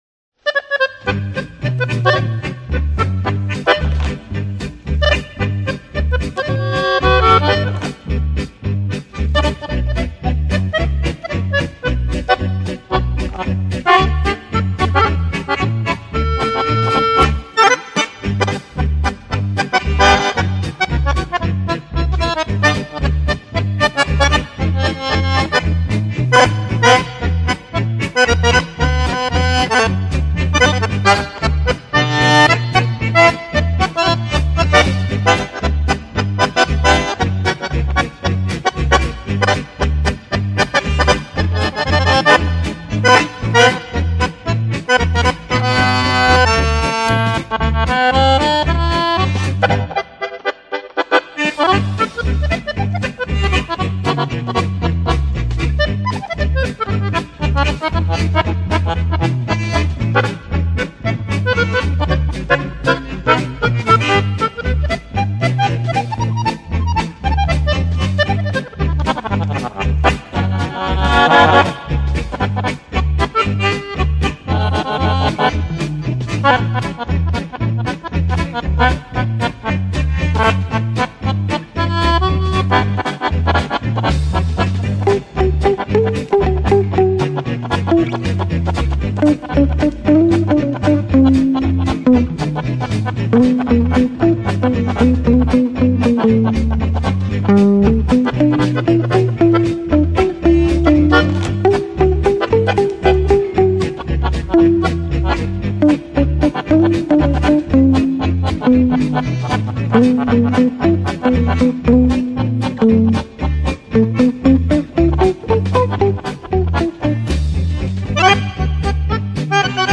Genre:Instrumental music, Accordion